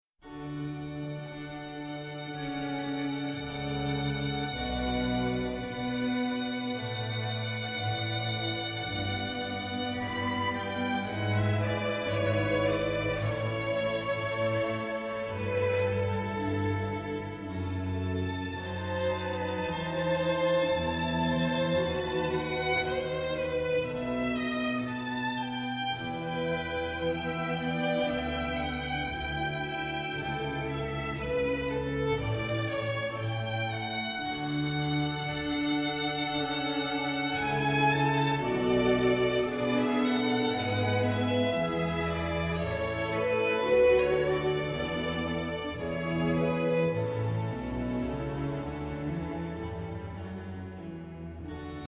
SUGERENCIAS DE REPERTORIO: TRÍO Y CUARTETO DE CUERDA
Instrumental